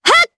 Ripine-Vox_Attack4_jp.wav